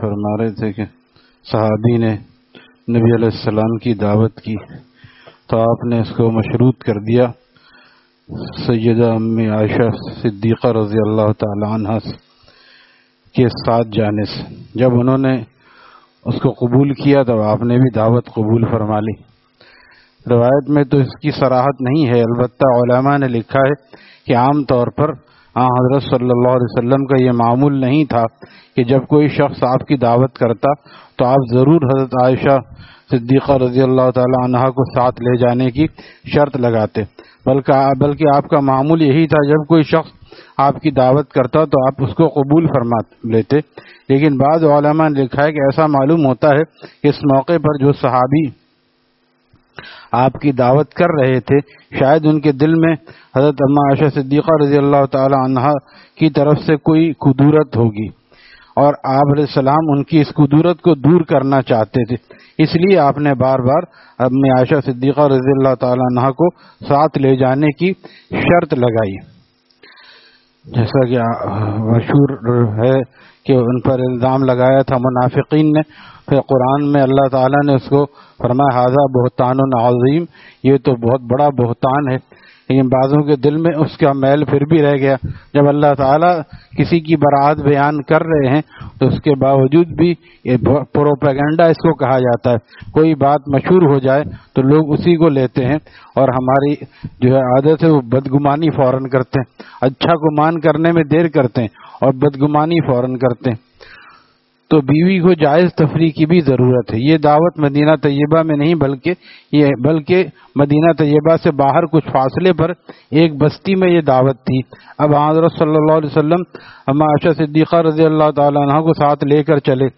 Taleem After Fajar at Jamia Masjid Gulzar e Mohammadi, Khanqah Gulzar e Akhter, Sec 4D, Surjani Town